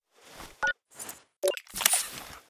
medkit_exo.ogg